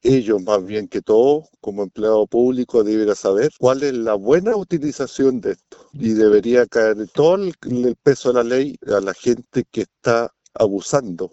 Mientras que el concejal Pedro Greves señaló que como funcionarios públicos deberían ser los primeros en saber el uso correcto de una licencia médica, por eso catalogó lo ocurrido de un abuso.